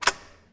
latch-01.ogg